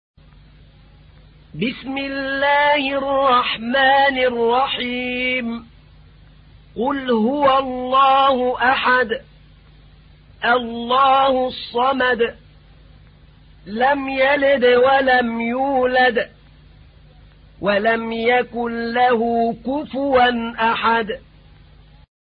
تحميل : 112. سورة الإخلاص / القارئ أحمد نعينع / القرآن الكريم / موقع يا حسين